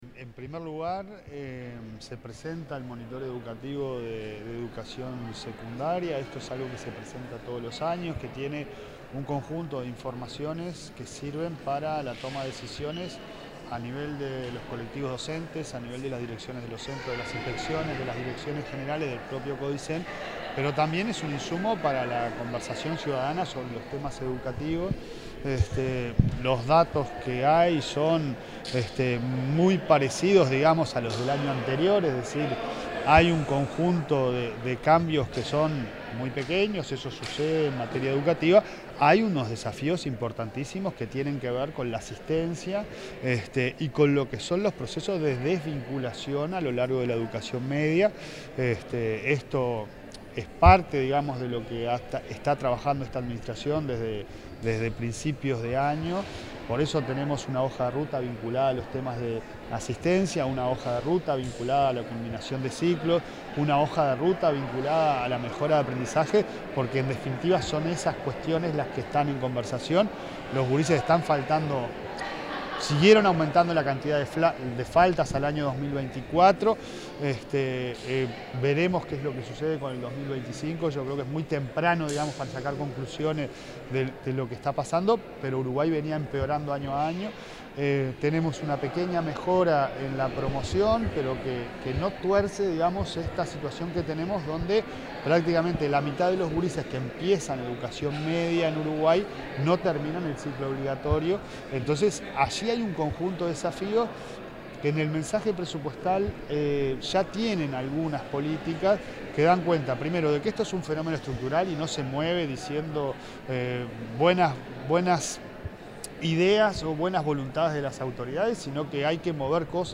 Declaraciones del presidente de la ANEP, Pablo Caggiani
El presidente de la Administración Nacional de Educación Pública (ANEP), Pablo Caggiani, realizó declaraciones en el marco de la presentación de los